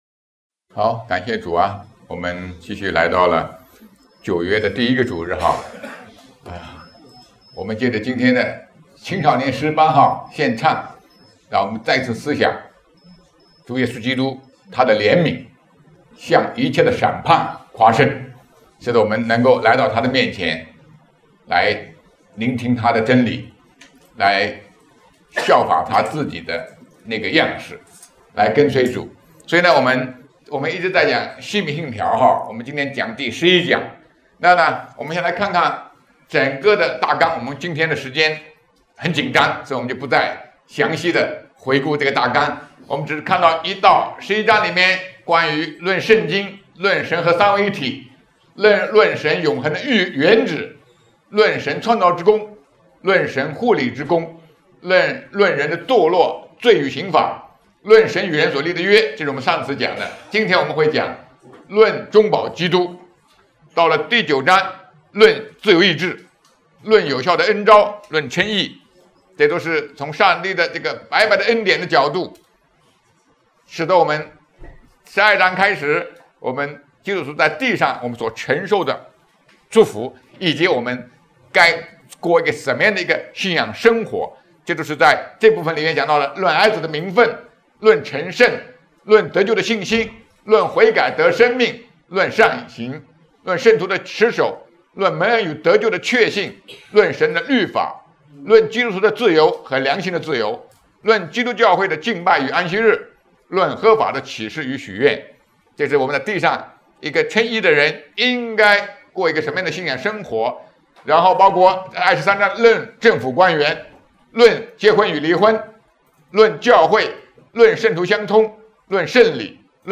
圣经讲道